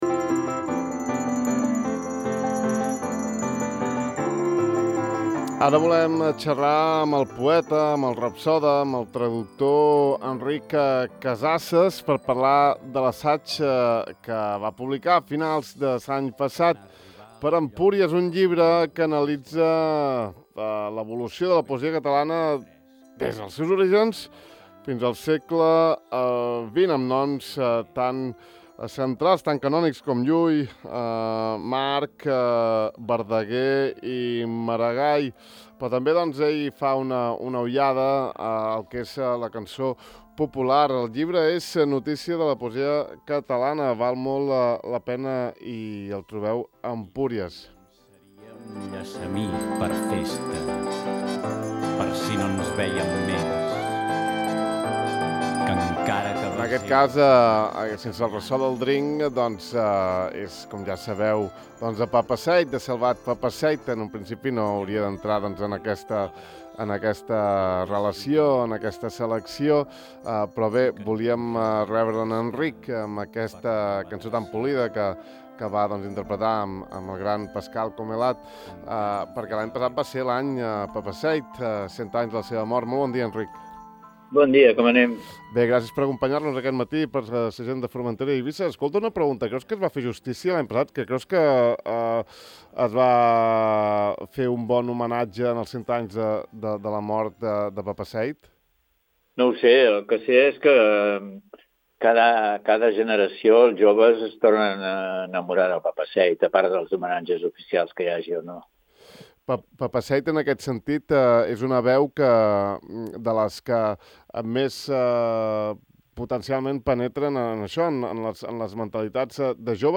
Hem entrevistat al poeta, rapsoda i traductor Enric Casasses sobre el seu llibre Notícia de la poesia catalana: